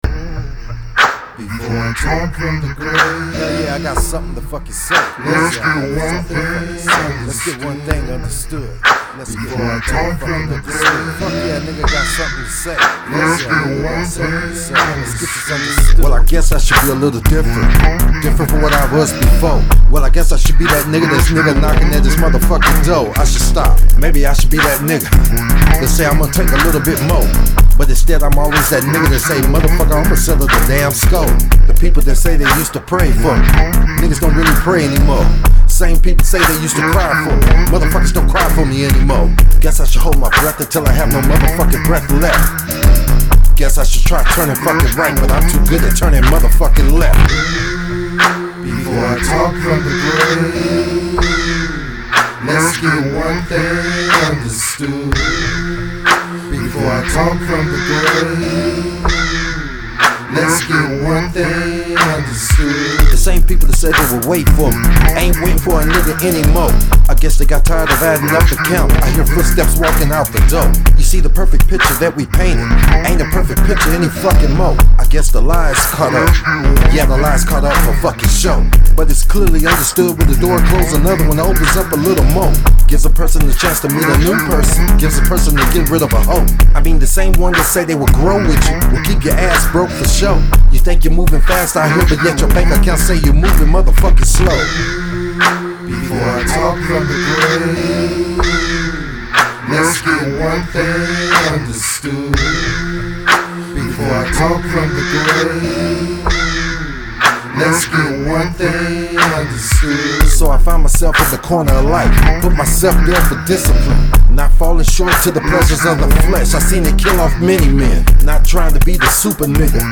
DARK RAP RAP